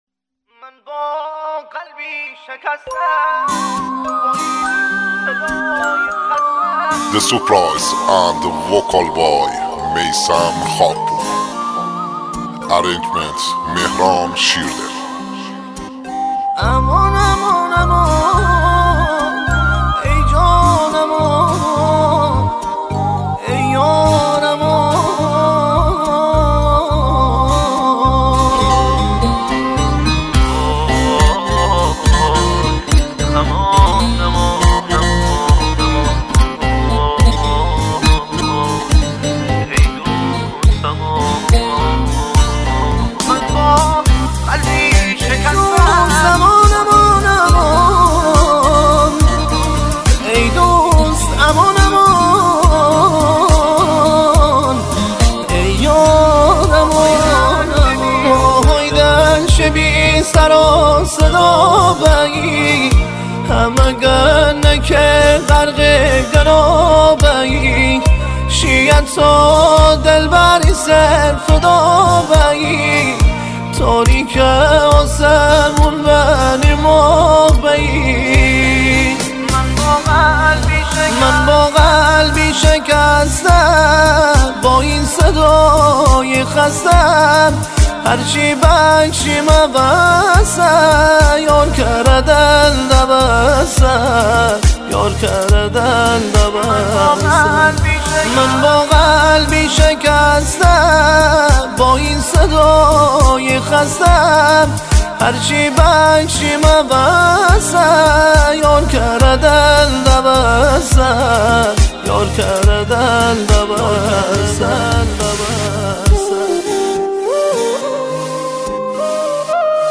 مازندرانی